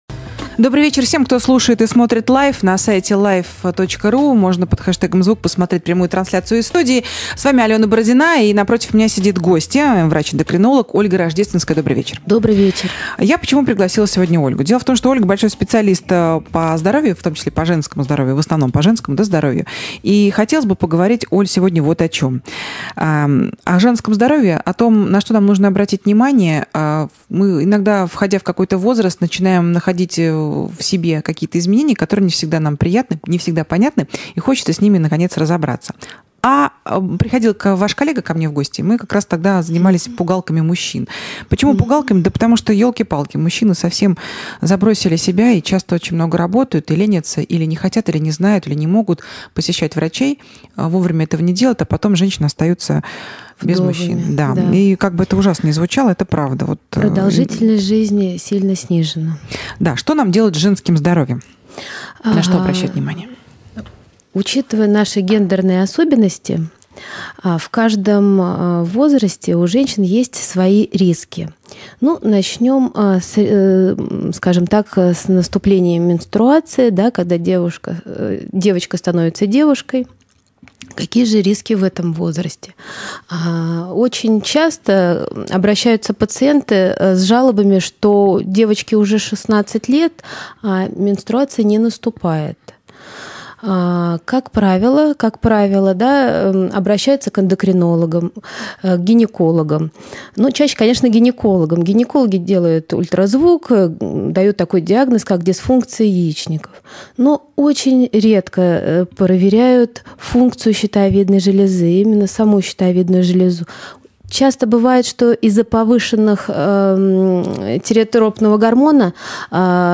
Источник: Радио Лайф